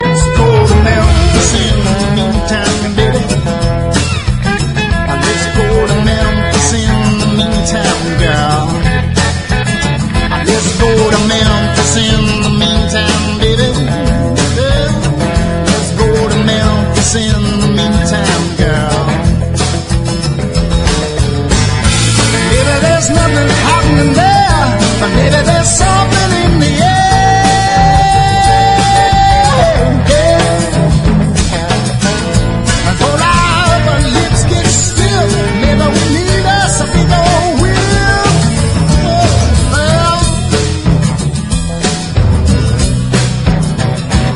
ROCK / 80'S/NEW WAVE. / NEW WAVE / 80'S / POWER POP / PUNK